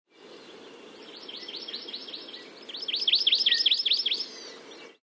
SoundsHawaiian is dedicated to gathering, archiving, and sharing the rich natural sounds of Hawai'i - to enhance awareness of the unique place Hawai'i holds in the panoply of life on the Earth.
hawaii-amakihi.mp3